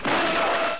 File: "Mazinger Z' step" (Un passo di Mazinga Z)
Type: Sound Effect